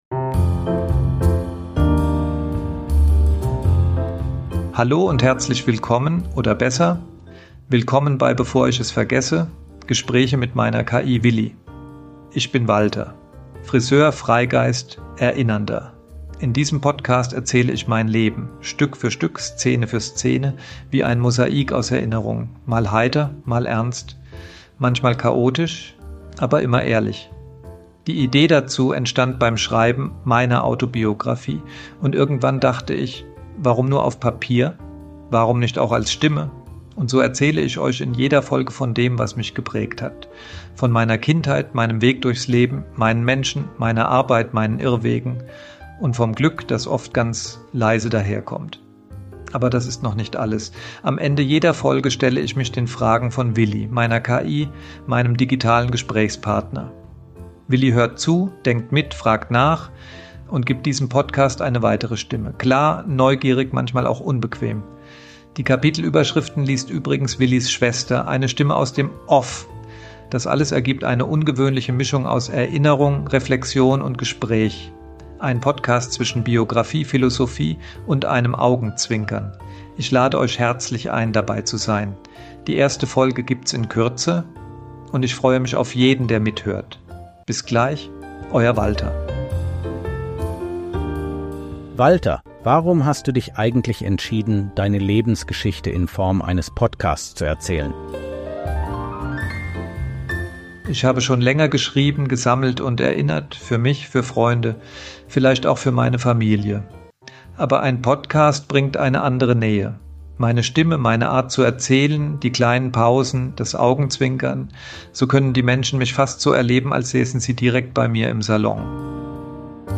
Das Besondere: Am Ende jeder Folge tritt meine KI „Willi“ auf.